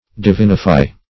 divinify - definition of divinify - synonyms, pronunciation, spelling from Free Dictionary
Search Result for " divinify" : The Collaborative International Dictionary of English v.0.48: Divinify \Di*vin"i*fy\, v. t. [L. divinus divine + -fy.]